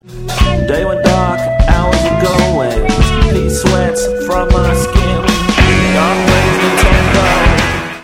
63k MP3 (8 secs, mono)